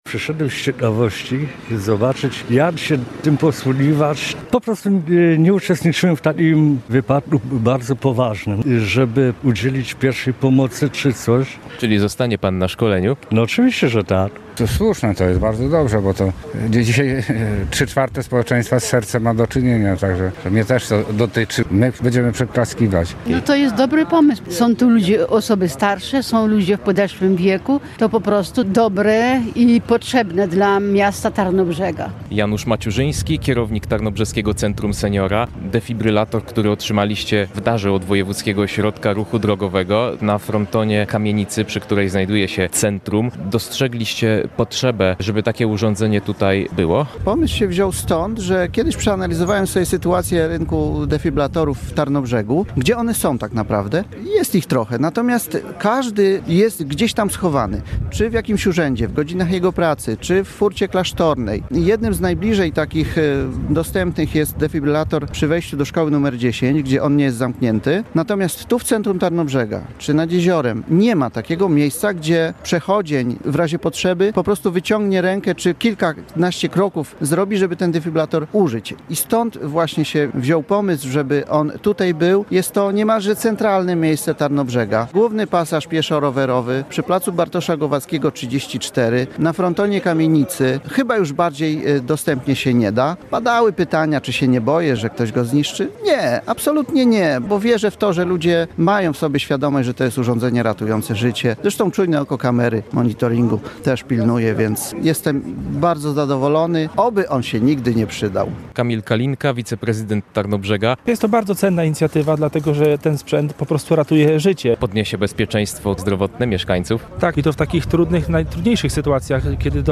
Dyspozytor zapyta, czy w pobliżu znajduje się AED. Jeśli tak, możemy go uruchomić i wykorzystać do akcji ratunkowej – mówił ratownik.